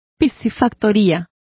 Also find out how piscifactoría is pronounced correctly.